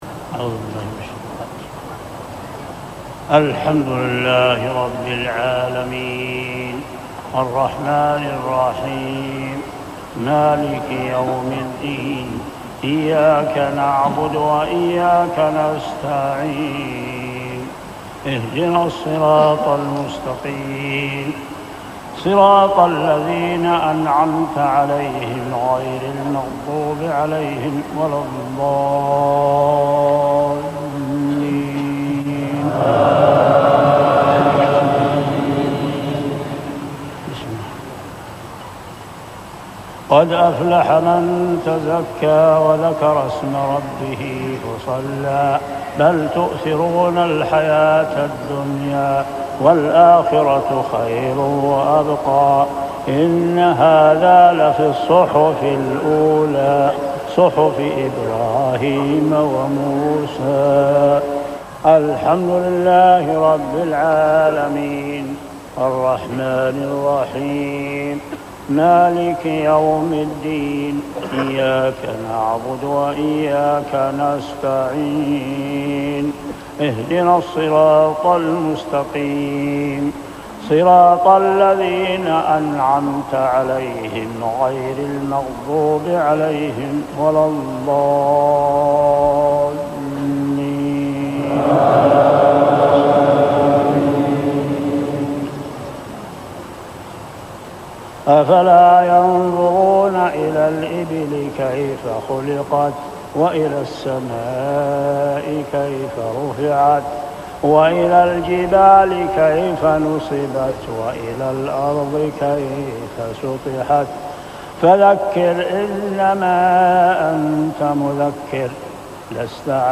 صلاة المغرب من آواخر شهر رمضان عام 1409هـ سورتي الأعلى 14-19 و الغاشية 17-26 | Maghreb prayer Surah Al-a’ala and Al-Ghashia > 1409 🕋 > الفروض - تلاوات الحرمين